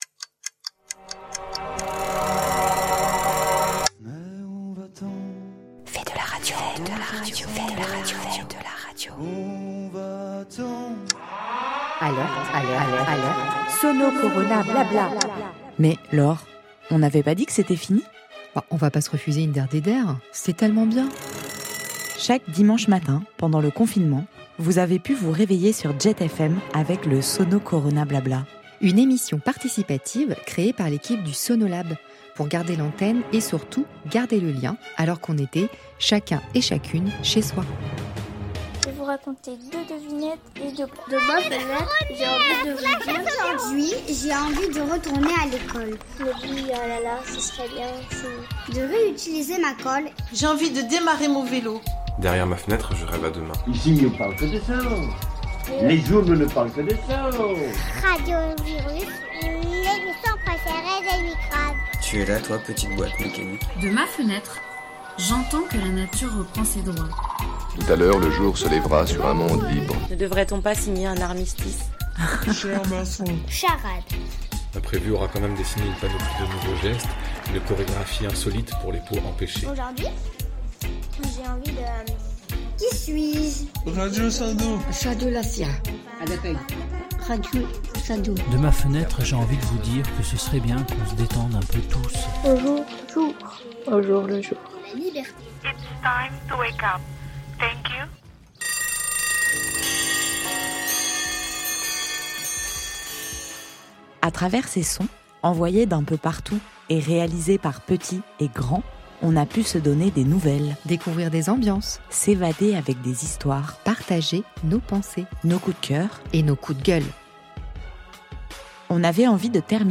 Armez de téléphones, de micros et autres enregistreurs environ 100 contributeurices, Oui 100 ! nous ont envoyé du son pour égayer nos oreilles et nos dimanche matin. On a pu entendre des ambiances, des histoires, des poèmes, des messages, des chansons, des coup de gueules, des coup de cœur, des bouts de vie de confinés, des blagues et des charades.